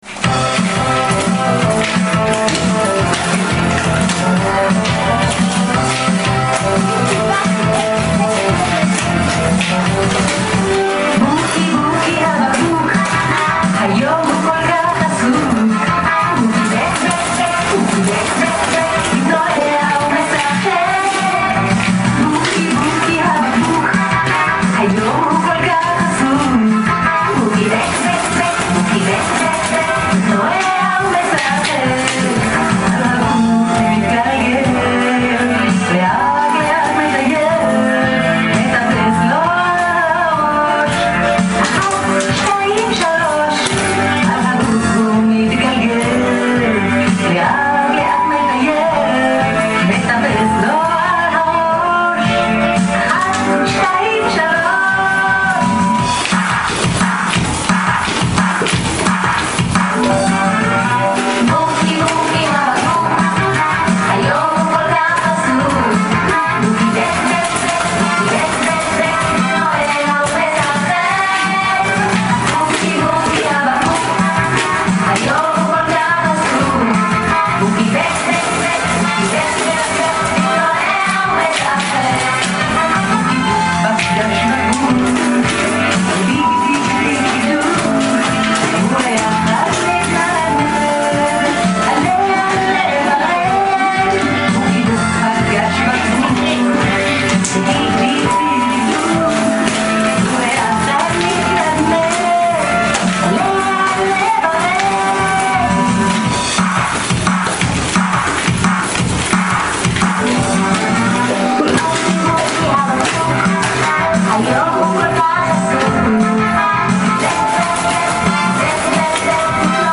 На последних секундах звучат аплодисменты и вроде бы слова -"молодец, браво", с акцентом.
Много шумов, возможно это ТВ-программа типа Музыкального Огонька.
Похоже, что запись велась на мобильник, на каком то мероприятии, под фонограмму.